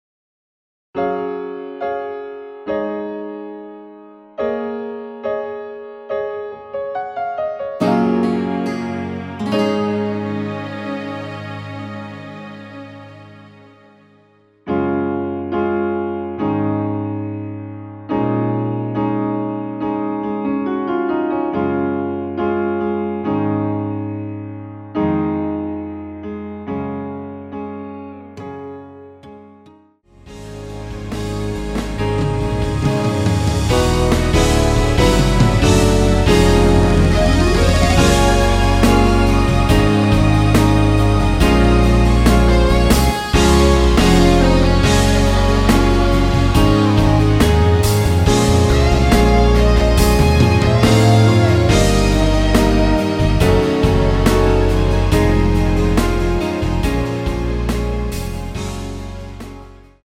-2)내린 MR
Db
◈ 곡명 옆 (-1)은 반음 내림, (+1)은 반음 올림 입니다.
앞부분30초, 뒷부분30초씩 편집해서 올려 드리고 있습니다.